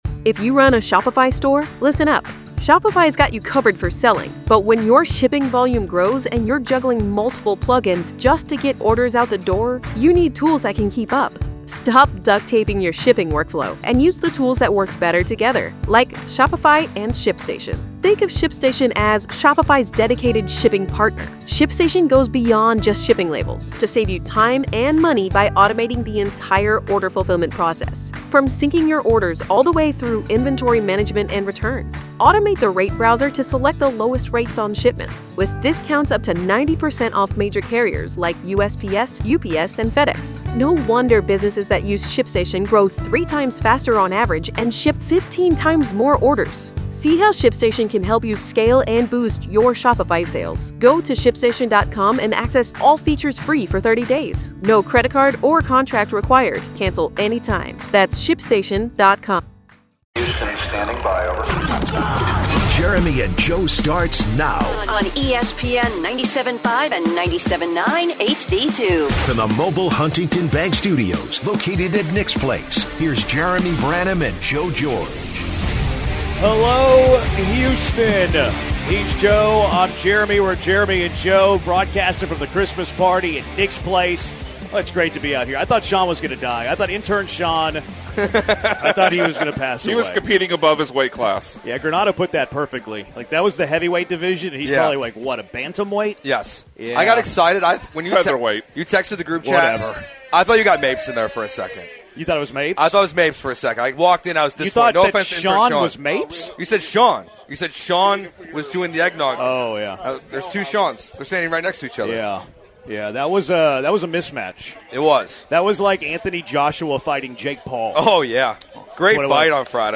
12/22 Hour 1- Texans Win vs Raiders, But Does C.J. Stroud Have A Consistency Problem?! - At Nick's Place For The 2025 Christmas Party!